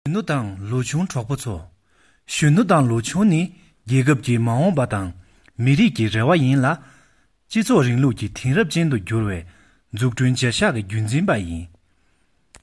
拉萨藏语-磁性男声